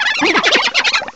Add all new cries
cry_not_croagunk.aif